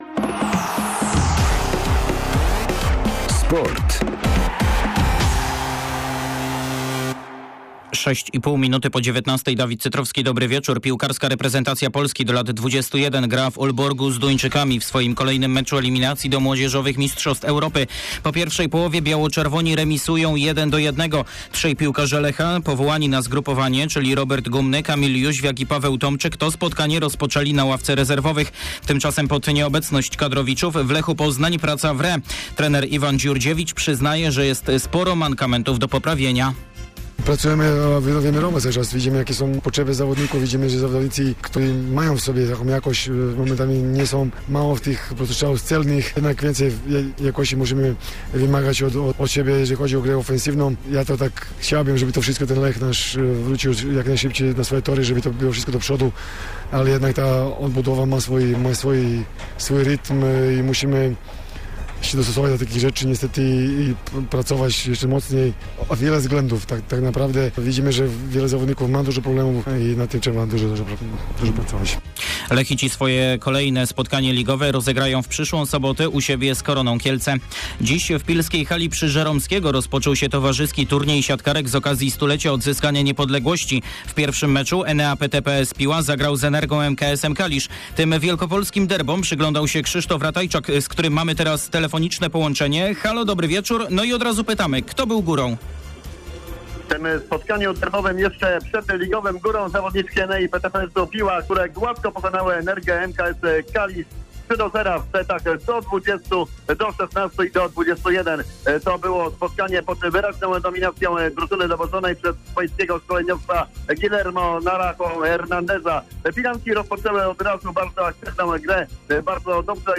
12.10. serwis sportowy godz. 19:05